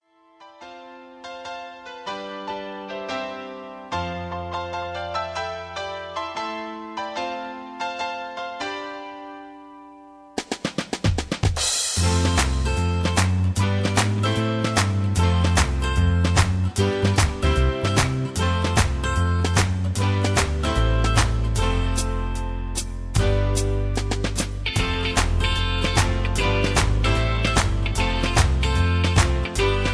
backing tracks
rock